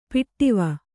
♪ piṭṭiva